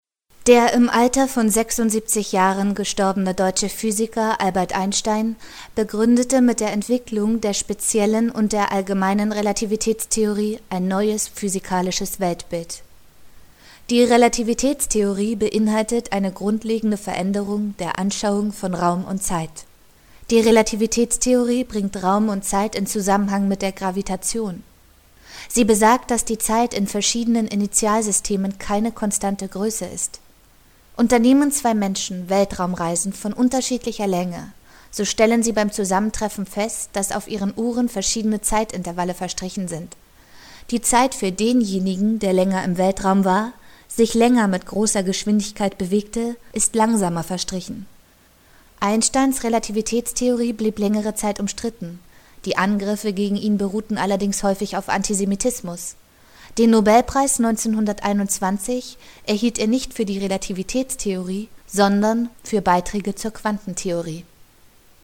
Hörspiel